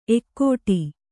♪ ekkōṭi